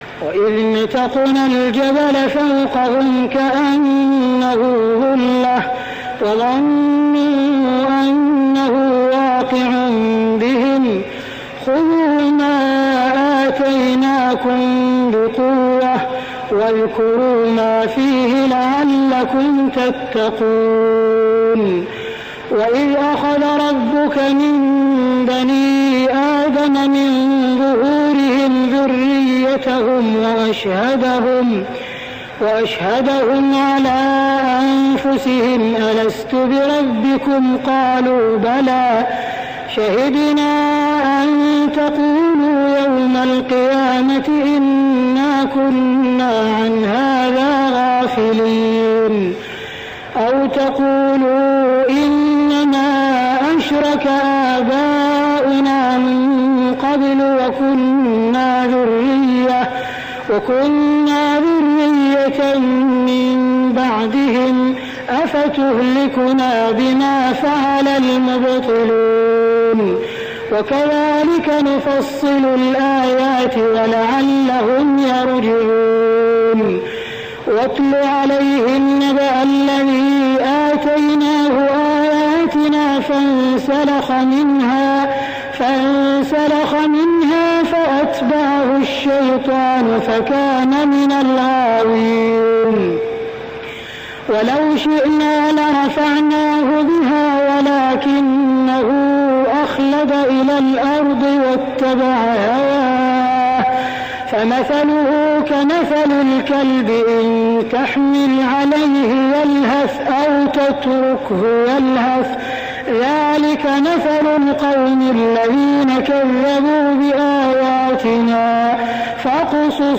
صلاة التراويح ليلة 9-9-1409هـ سورتي الأعراف 171-206 و الأنفال 1-40 | Tarawih Prayer Surah Al-A'raf and Al-Anfal > تراويح الحرم المكي عام 1409 🕋 > التراويح - تلاوات الحرمين